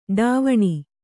♪ ḍāvaṇi